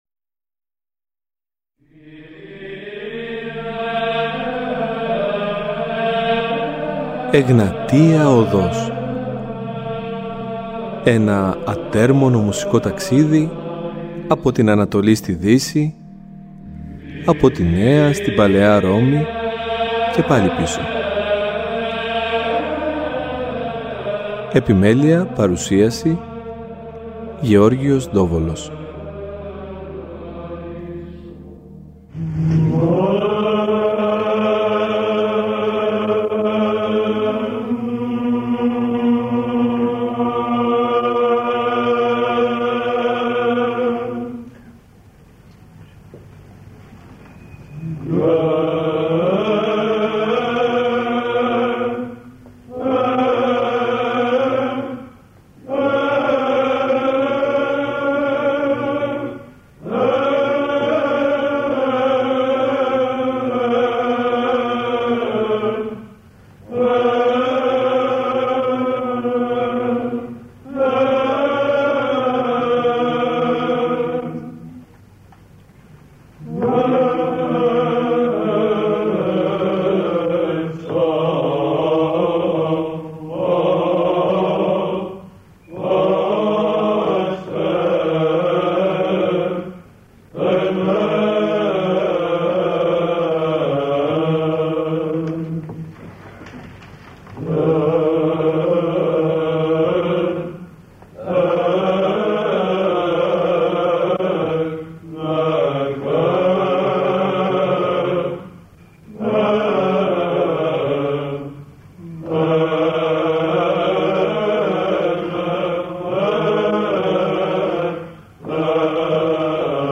Το αφιέρωμα μας στην ψαλτική της Θεσσαλονίκης συνεχίζεται και αυτό το Σαββατοκύριακο. Μαζί μας ένας ιεροψάλτης της νεότερης γενιάς, ο οποίος έχει ερευνήσει σε βάθος την τοπική αυτή παράδοση.
Θα ακούσουμε την μεγάλη χορωδία